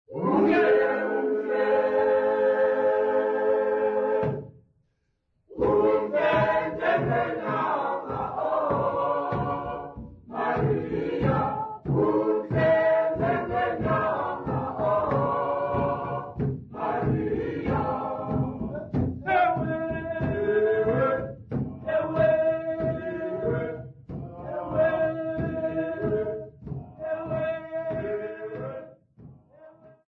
Xhosa festival workshop participants
Folk music
Sacred music
Field recordings
Xhosa festival workshop performance accompanied by drum
96000Hz 24Bit Stereo